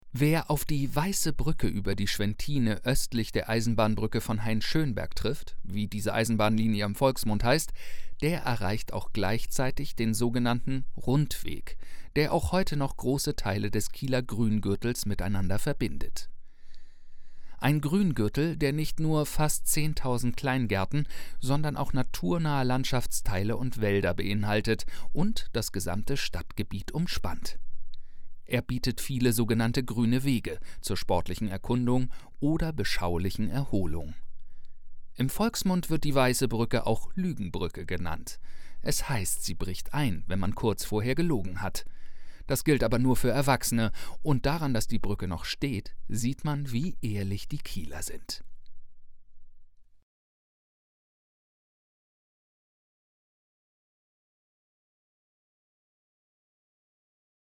Audioversion dieses Textes